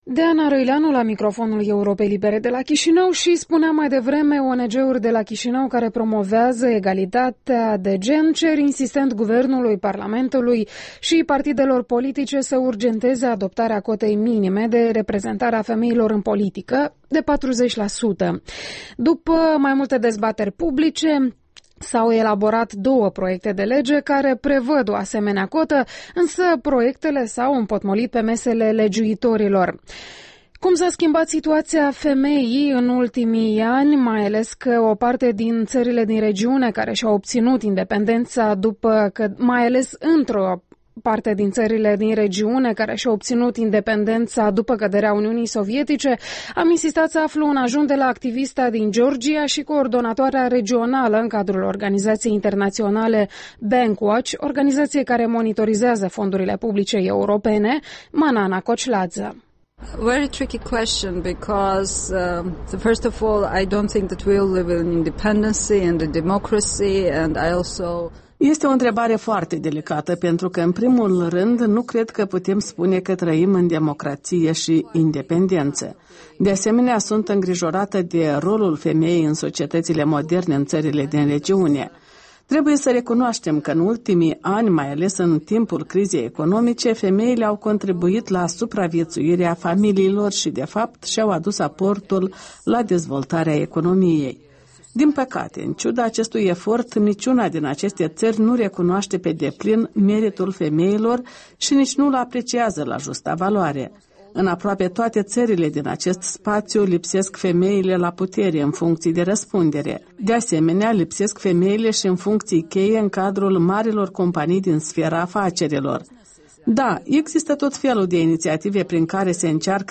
Interviul dimineții